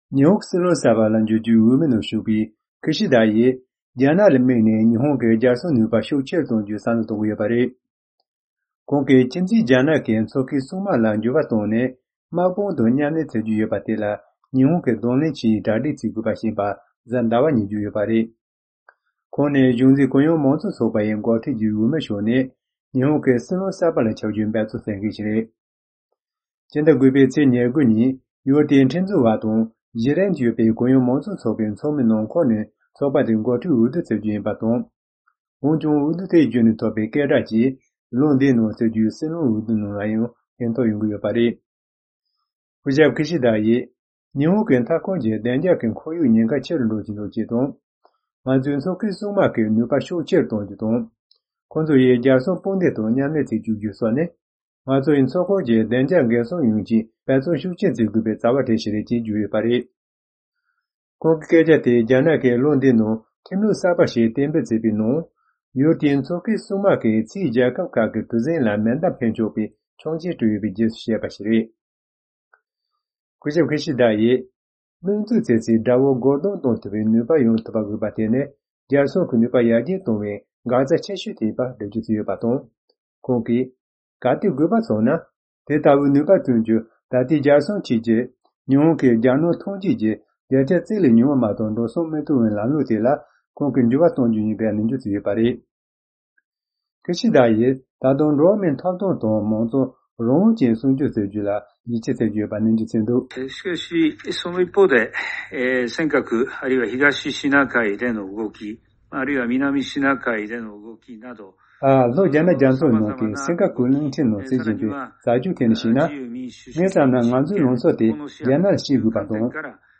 སྙན་སྒྲོན་ཞུ་གནང་གི་རེད།།